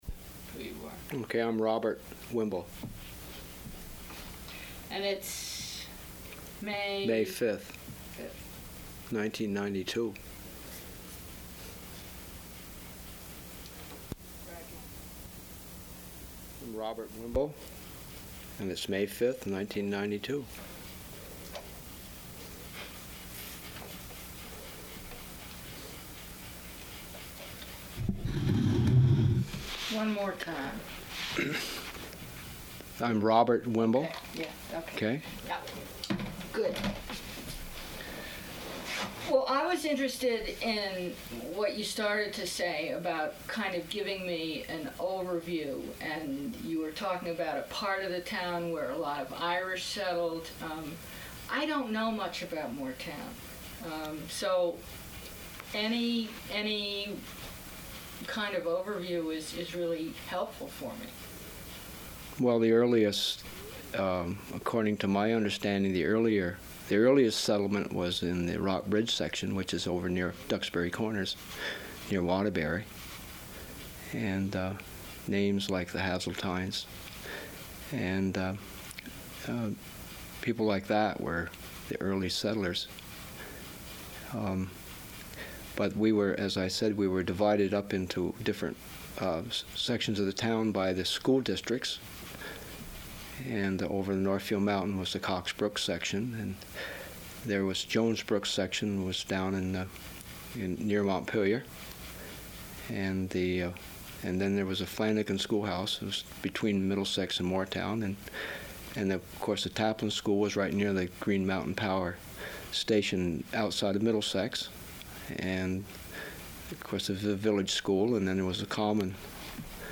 Interview
sound cassette (analog)